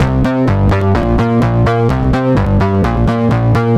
Pumping Fours C 127.wav